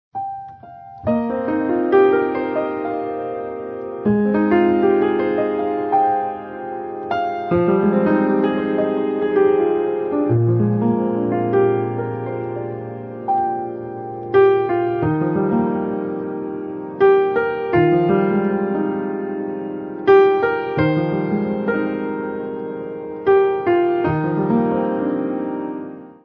Mainly Piano
Easy Listening